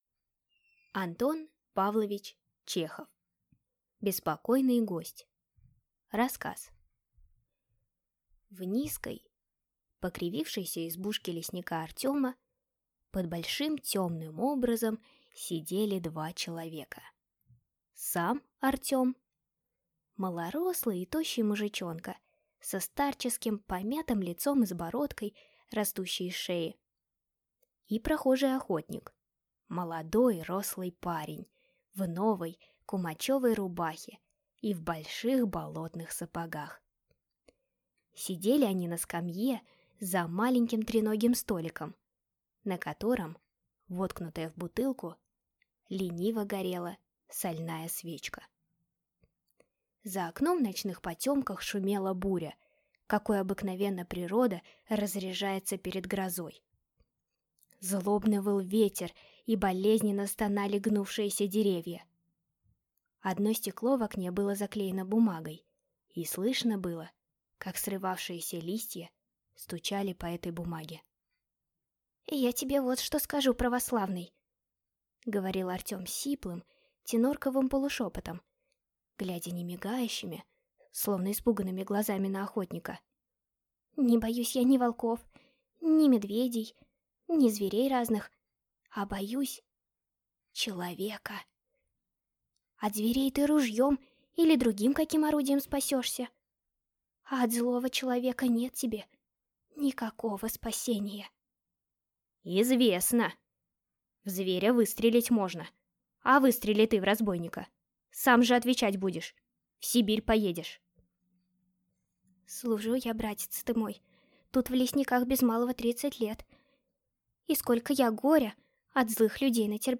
Аудиокнига Беспокойный гость | Библиотека аудиокниг